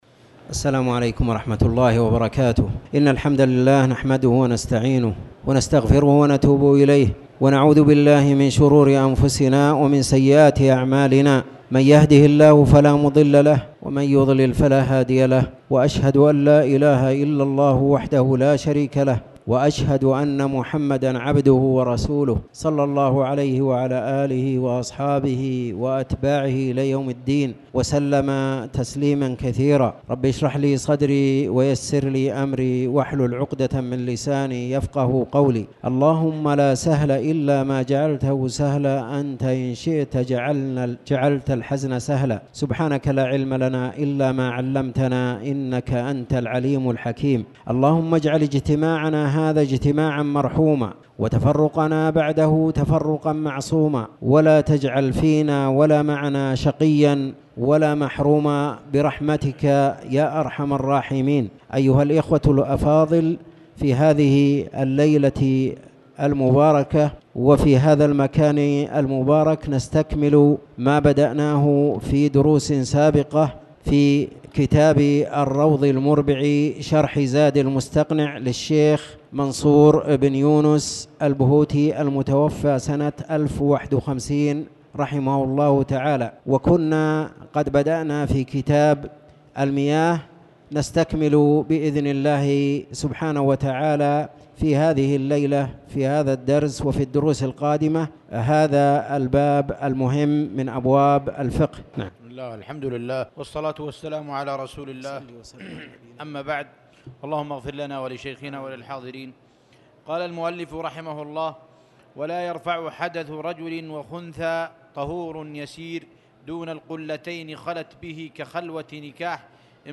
تاريخ النشر ٧ ربيع الثاني ١٤٣٩ هـ المكان: المسجد الحرام الشيخ